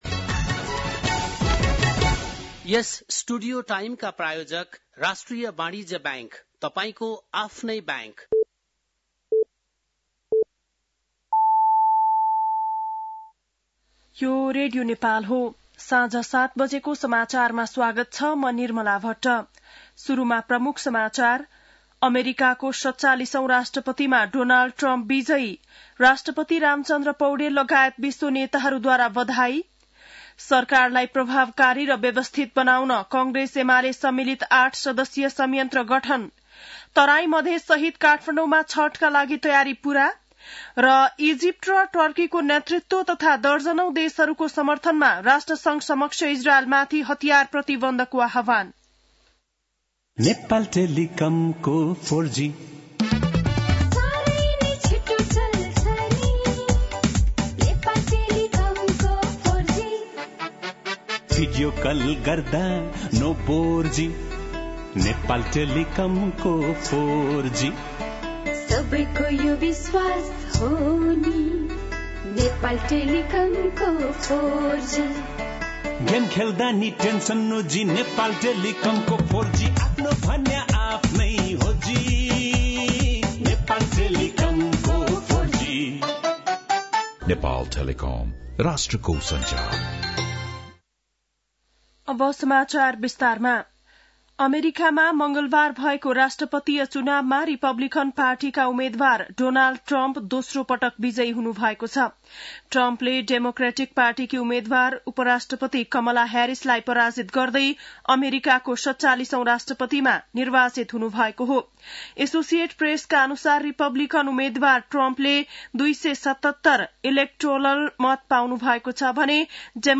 बेलुकी ७ बजेको नेपाली समाचार : २२ कार्तिक , २०८१
7-pm-news.mp3